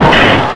CosmicRageSounds / ogg / general / highway / oldcar / clip4.ogg